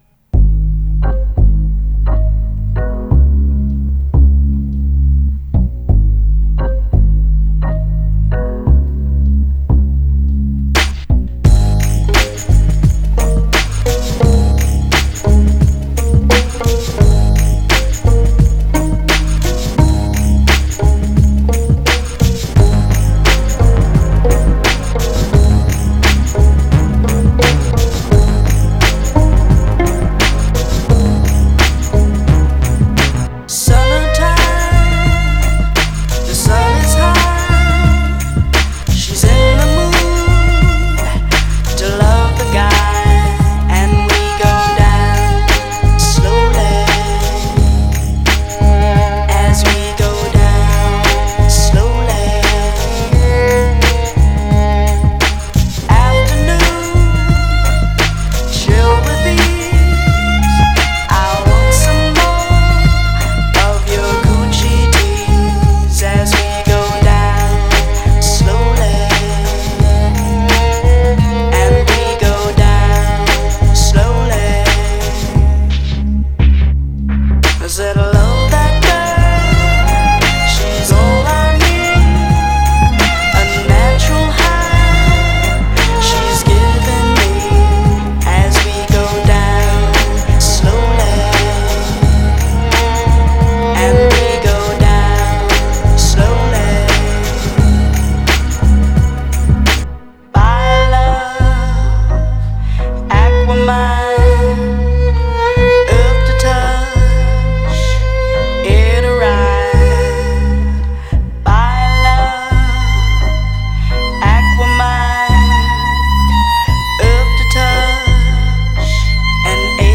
STRING-LOUNGE DEMO
Live-Violine